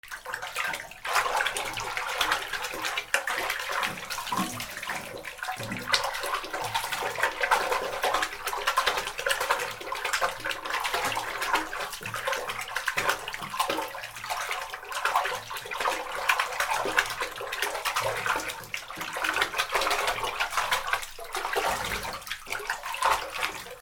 水音 水をかき回す
『バシャバシャ』